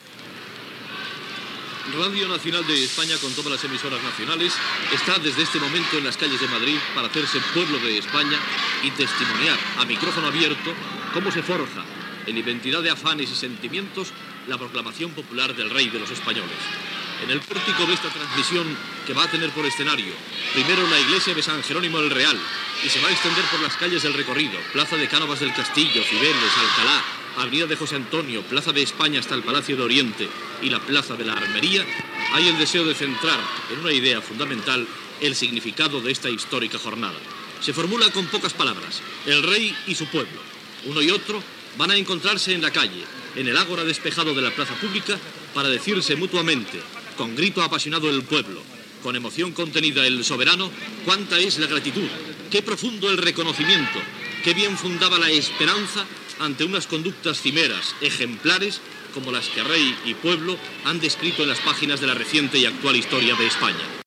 Transmissió des dels carrers de Madrid el dia de la cerimònia de la coronació del rei Juan Carlos I a la Iglesia de Los Jerónimos.
Informatiu